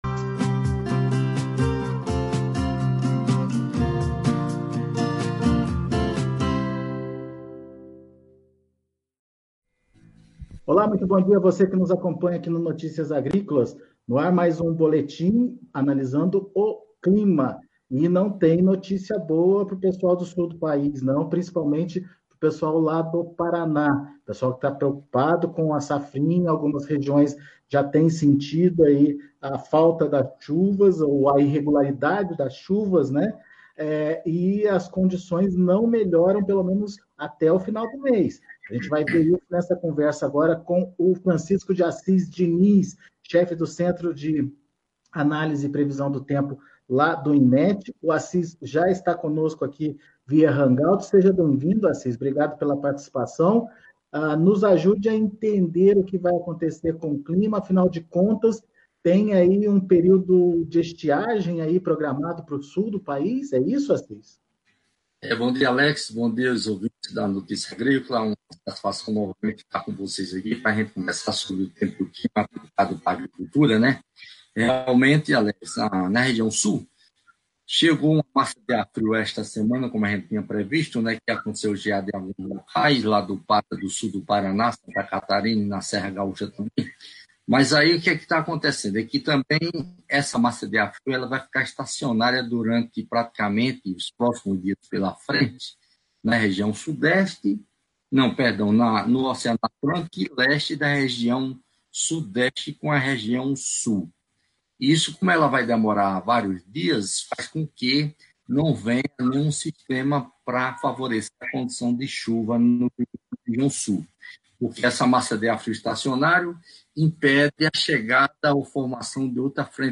Previsão do Tempo - Entrevista